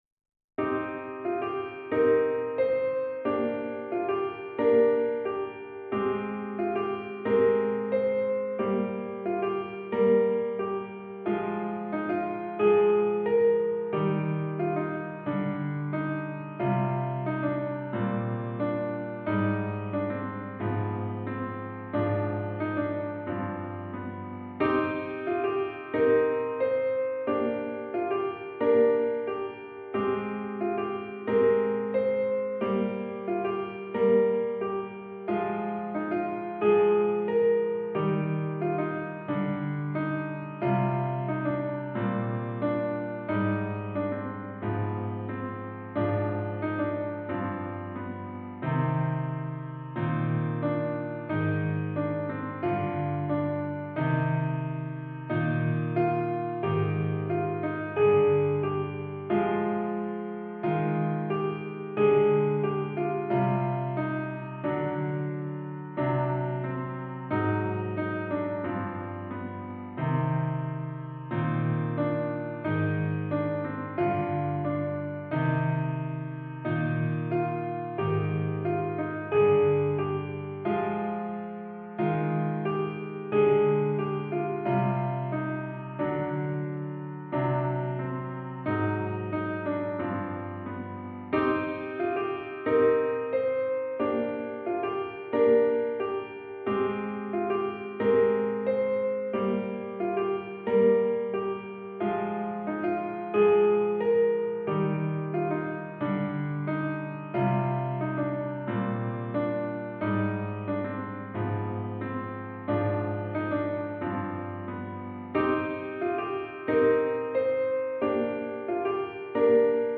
Another piece inspired by celtic folk traditions